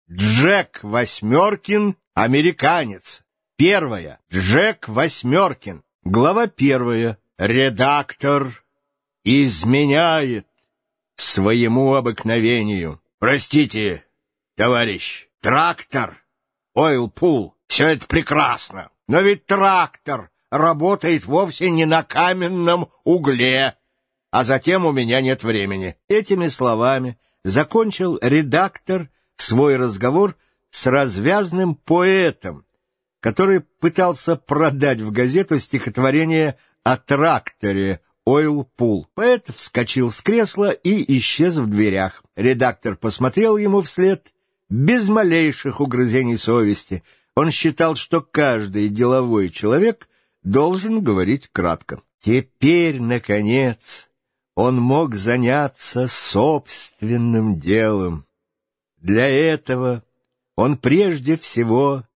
Аудиокнига Джек Восьмеркин – американец | Библиотека аудиокниг